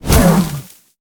Sfx_creature_pinnacarid_flinch_land_01.ogg